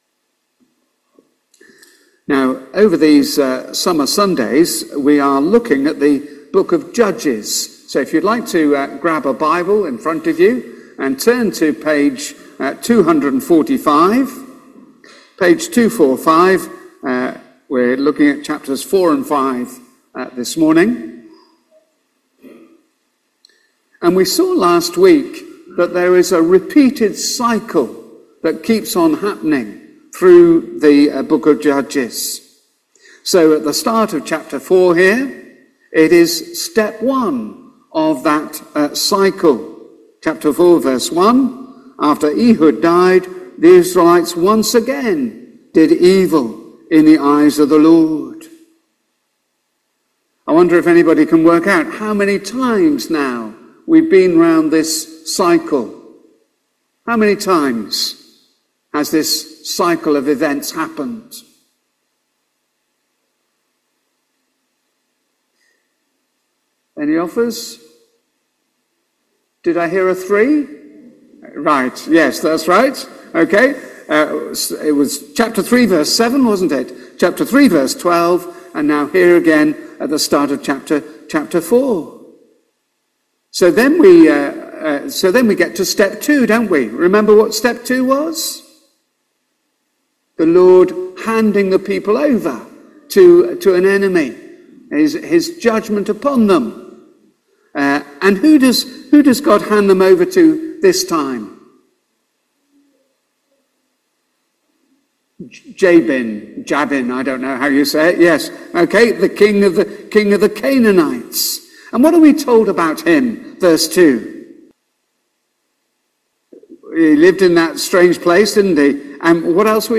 Reading starts at 7.17